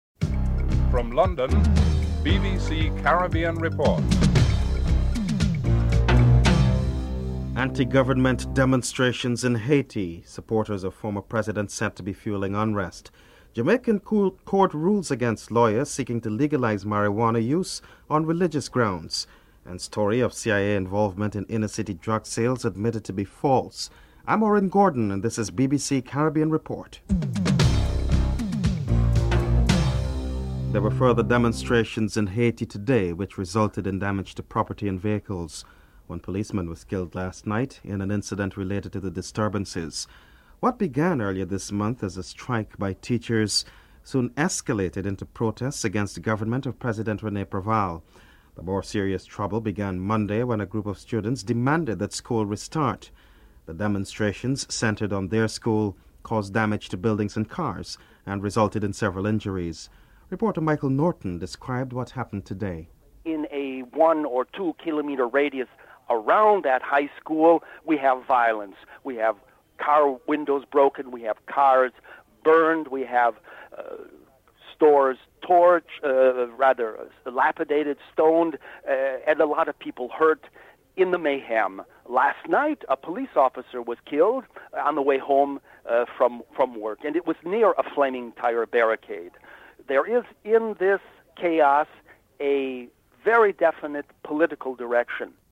Headlines (00:00-00:30)